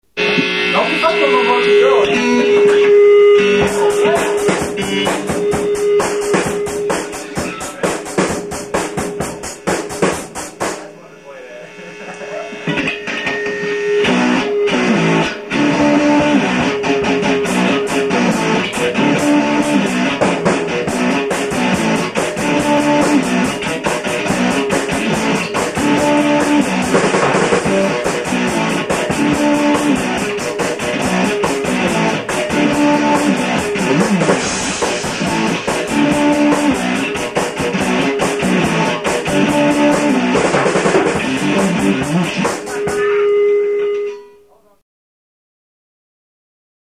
This little jam is great fun.